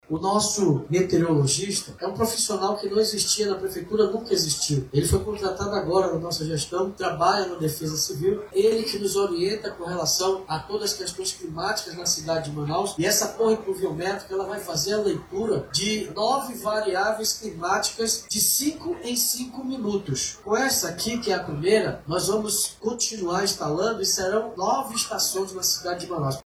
Ainda segundo o chefe do Executivo Municipal, as novas torres vão oferecer informações mais completas e precisas sobre o clima na capital amazonense.